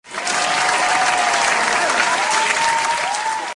(APPLAUSE)
Tags: gutteral